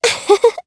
Lorraine-Vox_Happy2_jp.wav